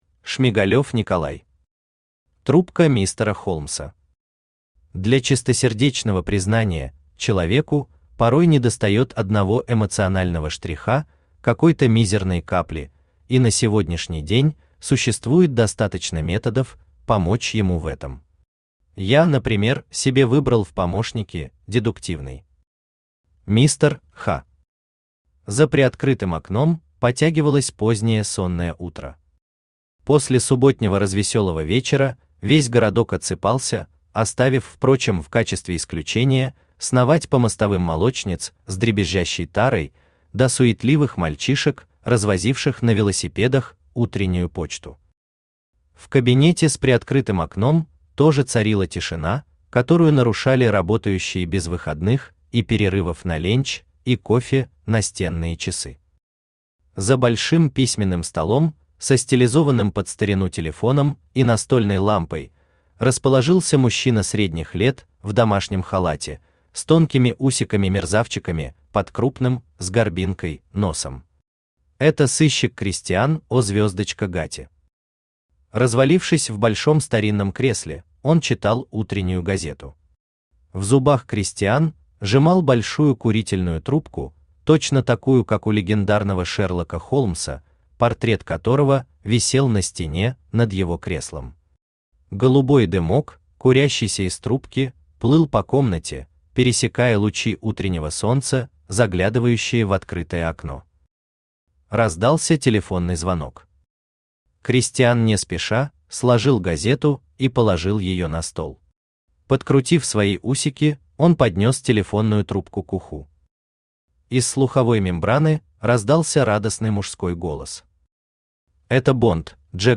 Аудиокнига Трубка мистера Холмса | Библиотека аудиокниг